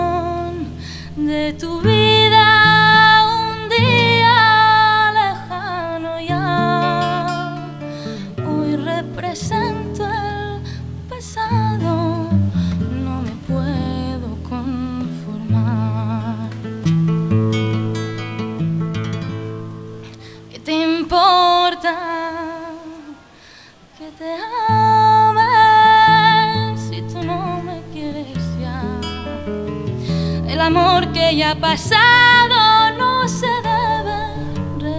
Жанр: Фолк